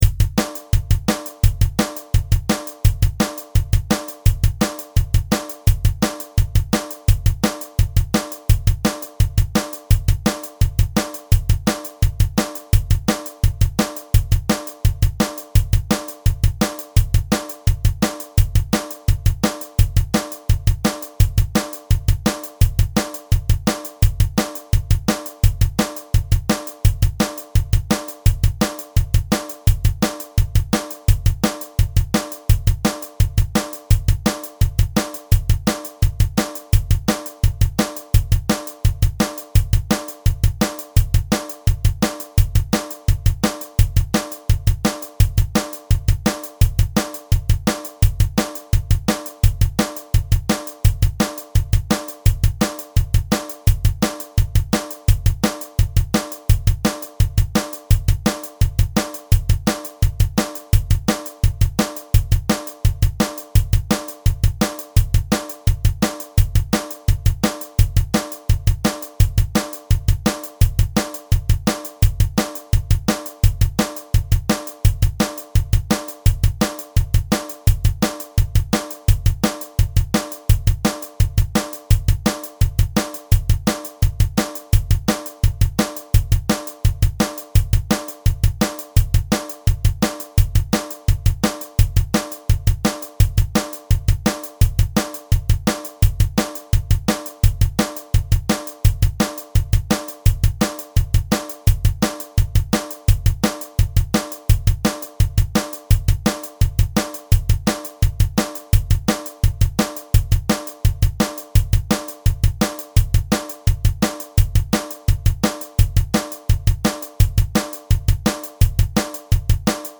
Drum Track